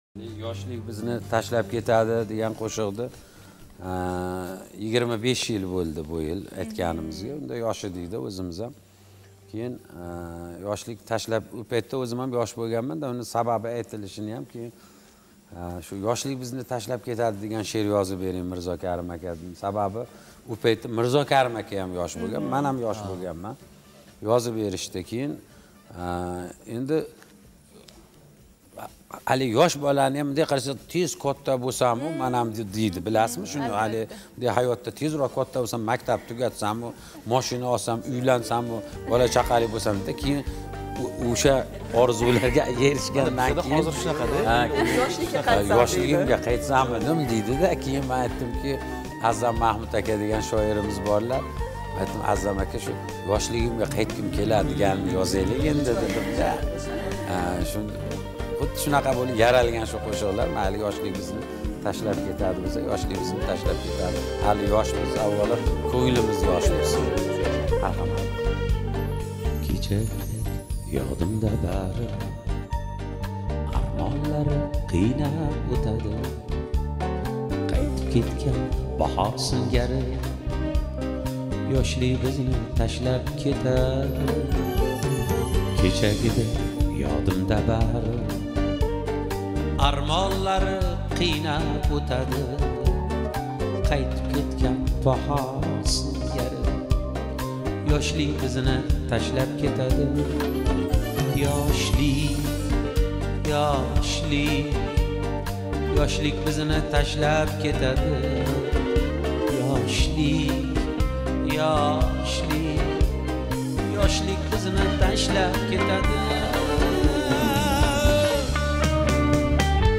duet jonli ijro 2023